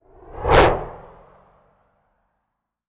bullet_flyby_designed_03.wav